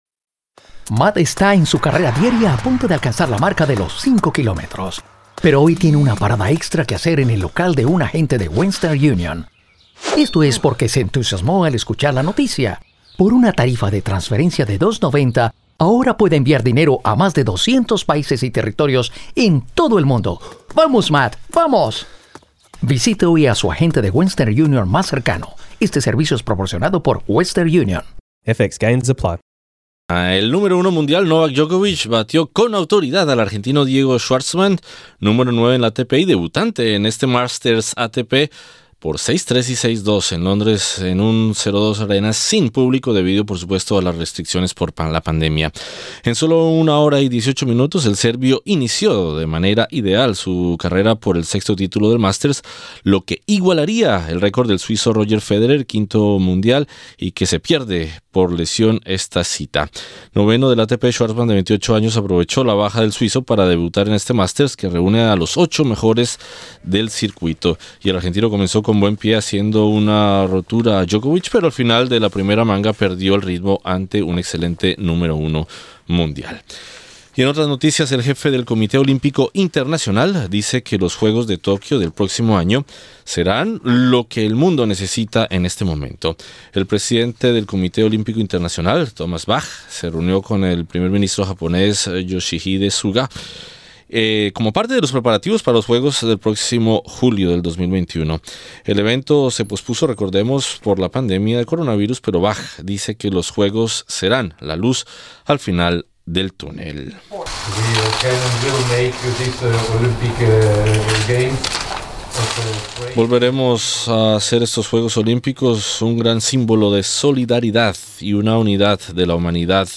El tenista número 1 mundial Novak Djokovic batió con autoridad al argentino Diego Schwartzman en Londres. Escucha esta y otras noticias deportivas del día.